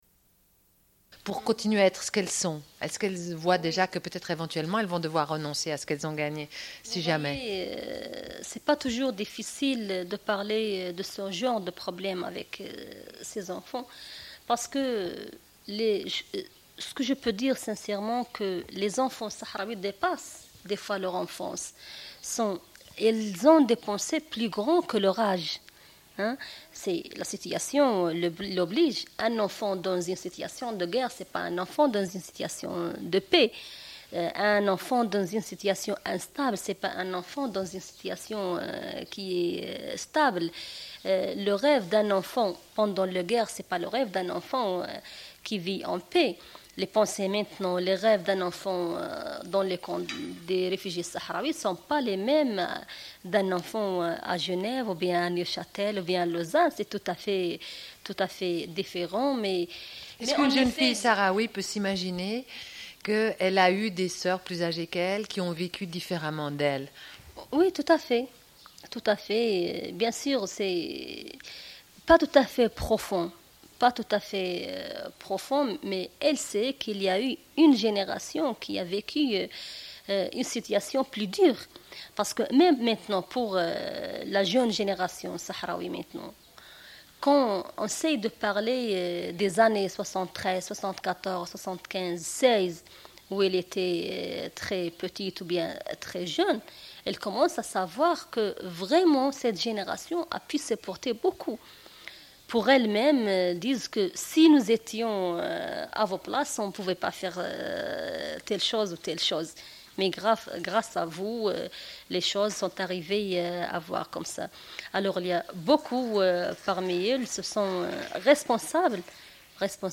Une cassette audio, face A31:15